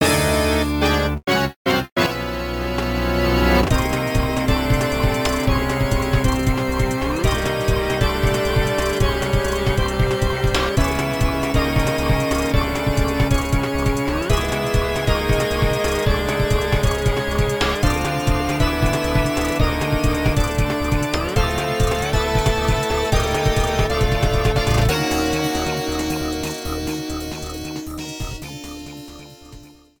Fade out added